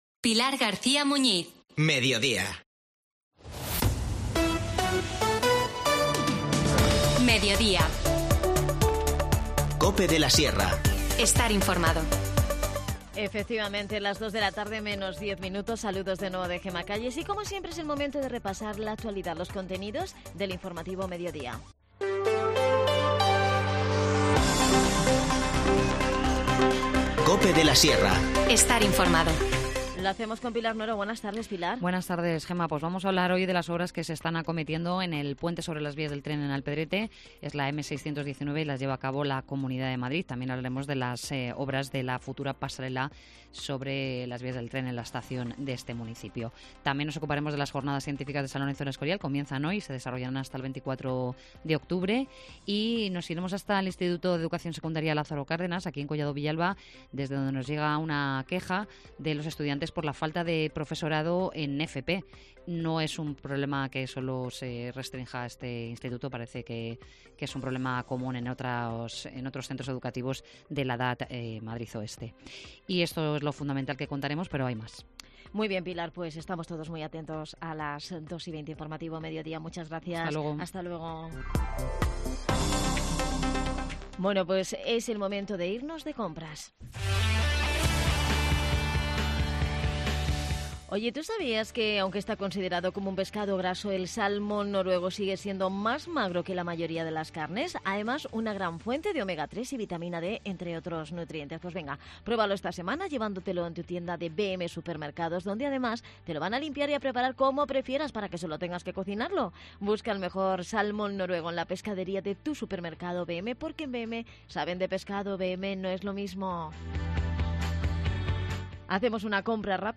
AUDIO: Hoy en Collado Villalba Capital de la Sierra Adan Martínez, concejal de Comunicación nos habla de la nueva edición del...
Las desconexiones locales son espacios de 10 minutos de duración que se emiten en COPE, de lunes a viernes.